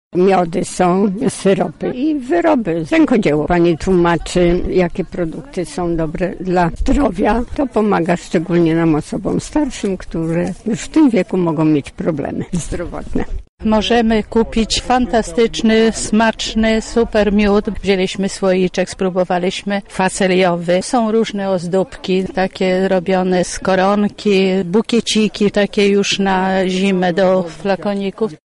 Nasza reporterka zapytała uczestników o wrażenia: